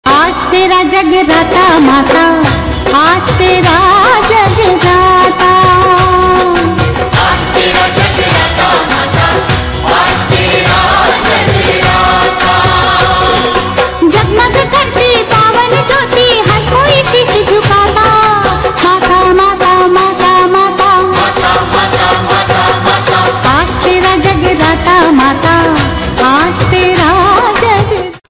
devotional